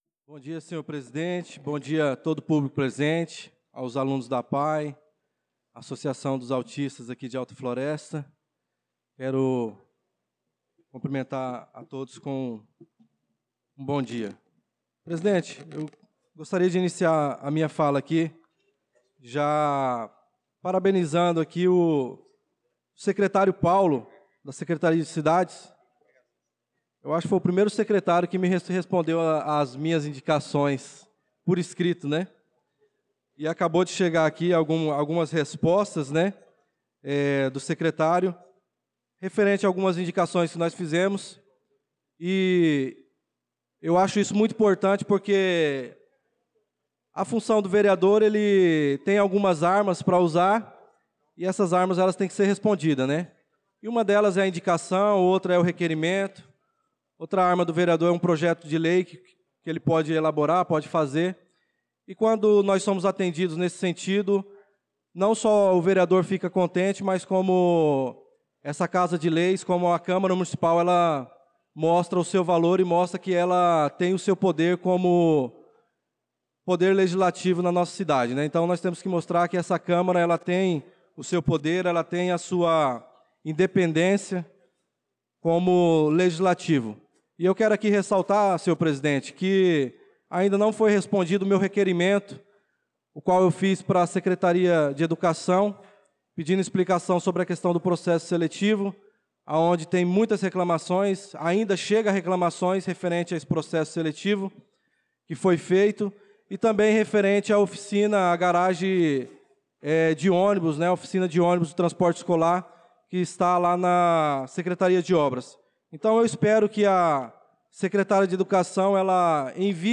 Pronunciamento do vereador Darlan Carvalho na Sessão Ordinária do dia 01/04/2025